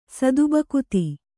♪ sadu bakuti